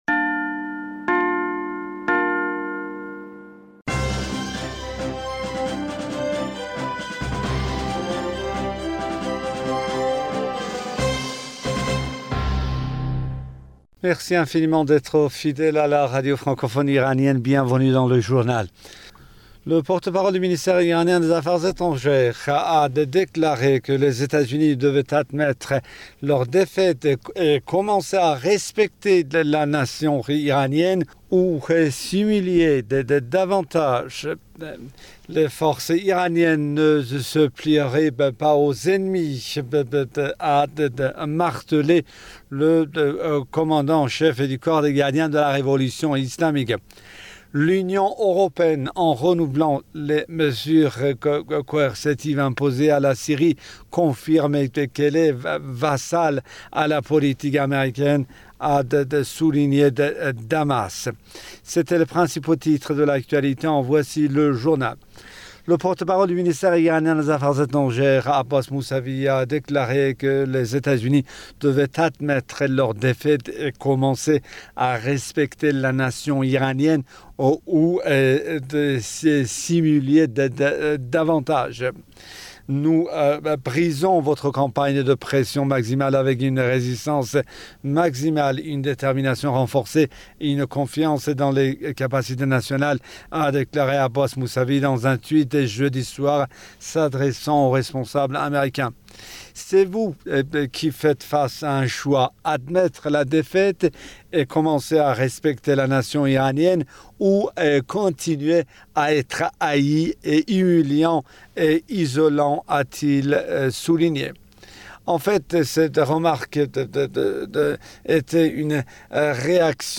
Bulletin d'information du 29 mai 2020